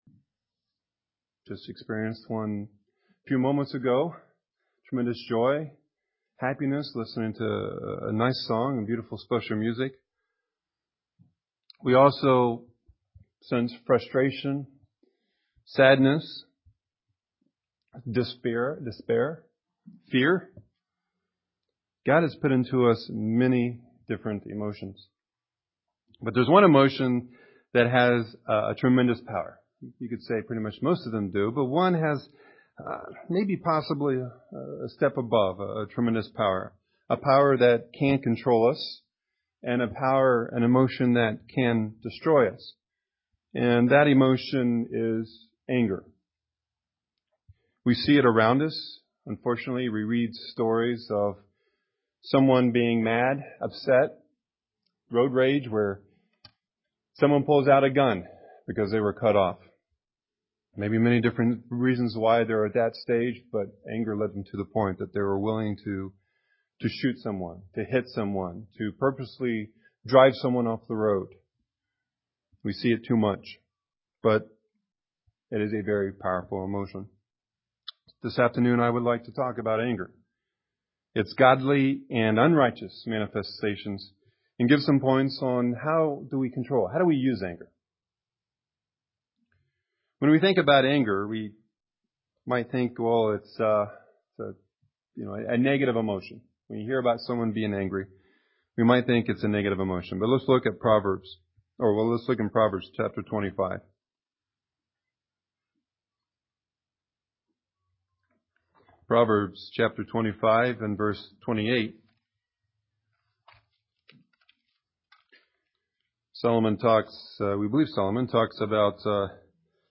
Given in Milwaukee, WI
UCG Sermon Studying the bible?